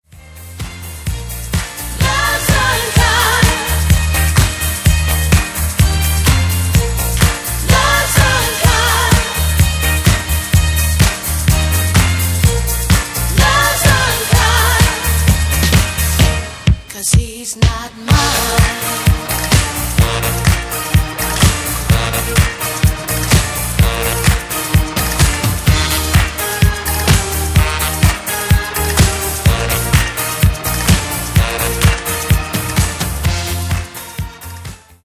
Remastered High Definition
Genere:   Disco